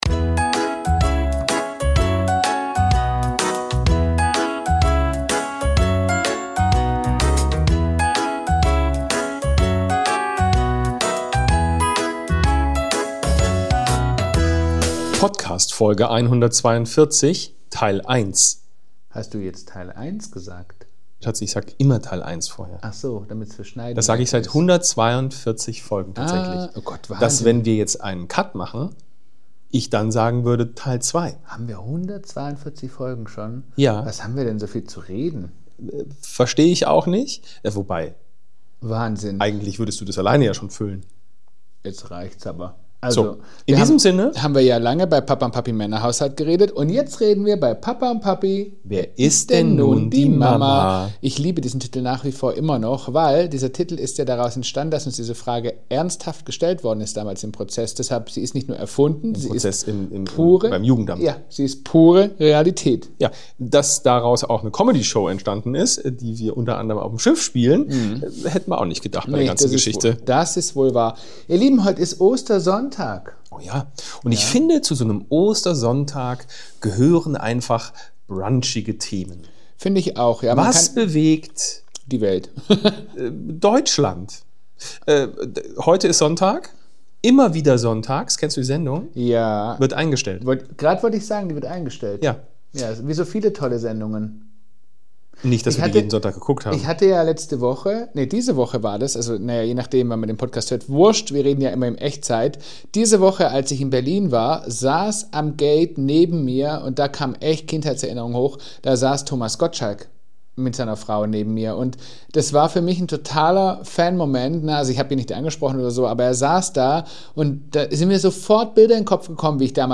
Es wird gelacht, gedacht, abgeschweift und wieder eingesammelt.